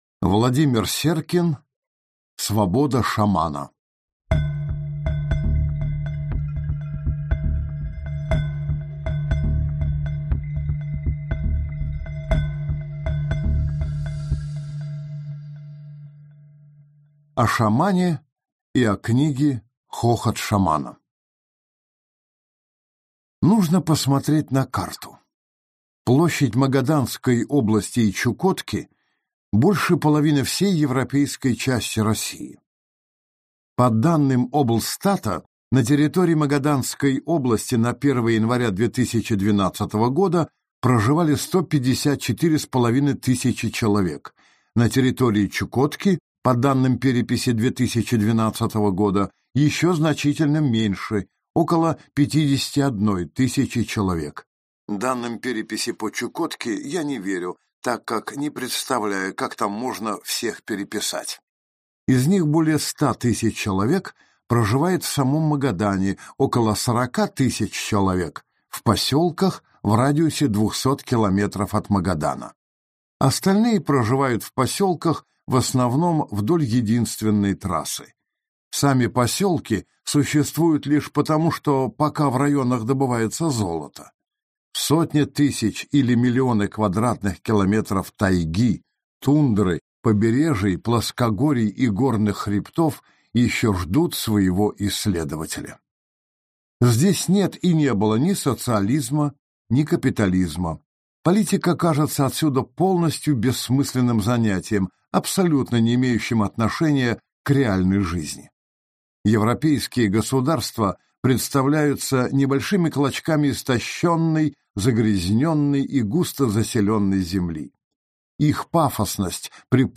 Аудиокнига Свобода шамана | Библиотека аудиокниг